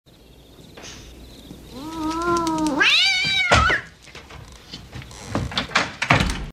CAT MEOWS NERVOUS.mp3
Anxious cat scared by a a neighbor.
.WAV .MP3 .OGG 0:00 / 0:07 Type Mp3 Duration 0:07 Size 1,1 MB Samplerate 44100 Hz Bitrate 256 kbps Channels Stereo Anxious cat scared by a a neighbor.
cat_meows_nervous_r55.ogg